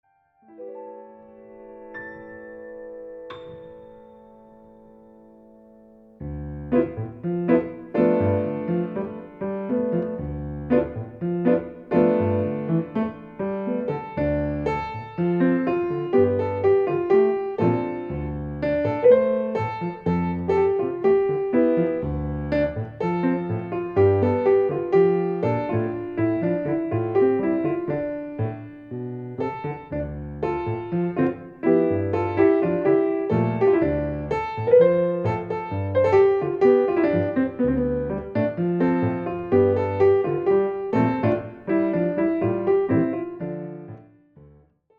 This music is wonderful for relaxation and meditation.